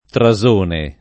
[ tra @1 ne ]